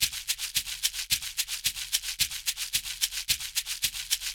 APH SHAKER-R.wav